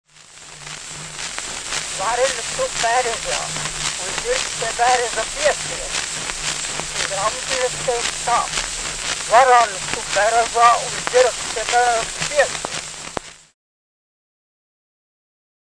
Latviešu valodas dialektu skaņu pieraksti : vaska ruļļi
Fonogrāfa ieraksti
Latviešu valodas dialekti